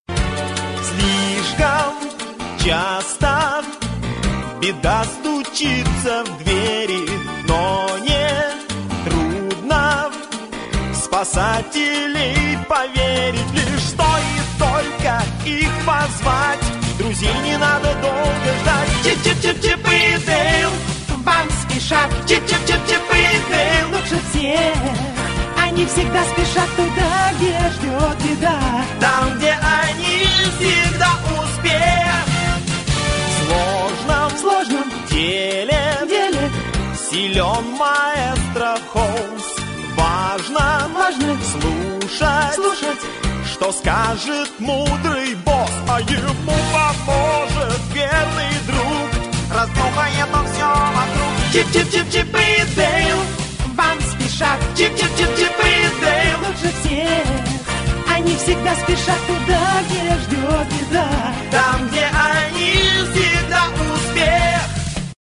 Заставка на русском языке мажорная, тематическая.